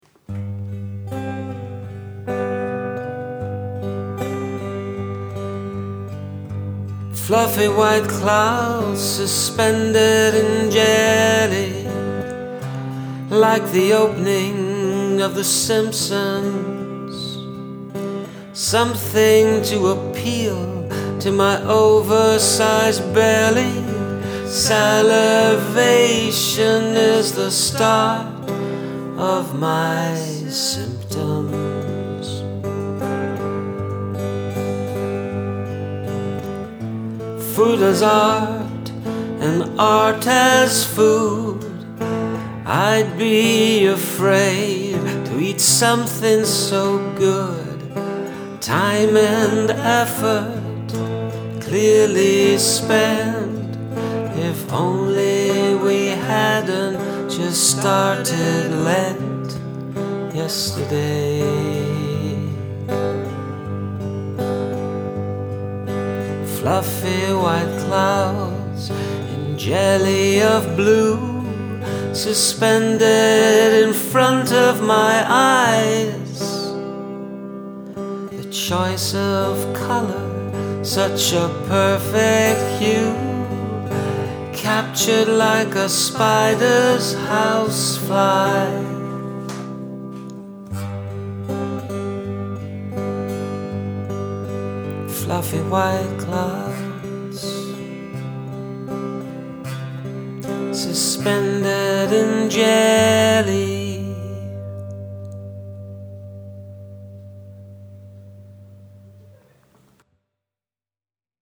I'm not sure the tempo is quite right at the start, but that can be worked on.